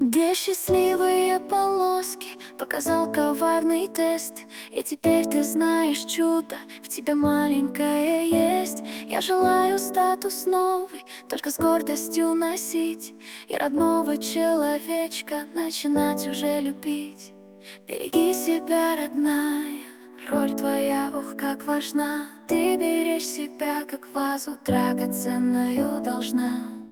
Мелодии на гендер пати, фоновая музыка, песни, демо записи: